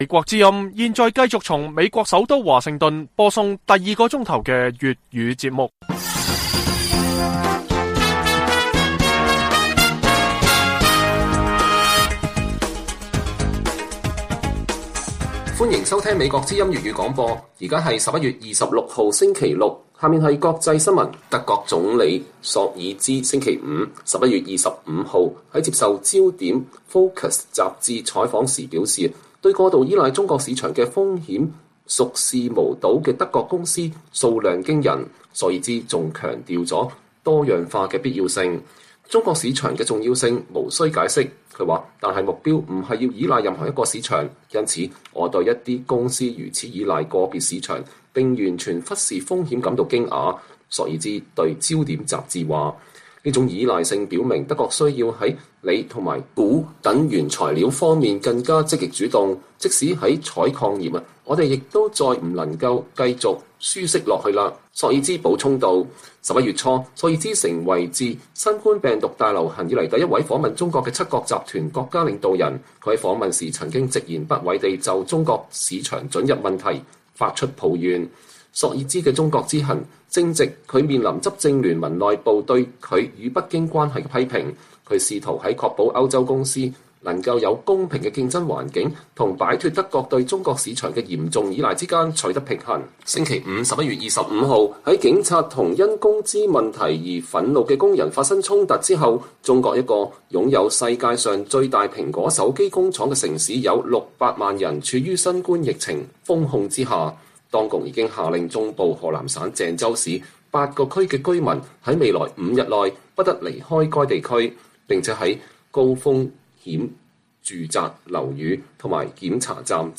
粵語新聞 晚上10-11點 : 中國出台網絡審查新規定 點贊“不良信息”或將被追責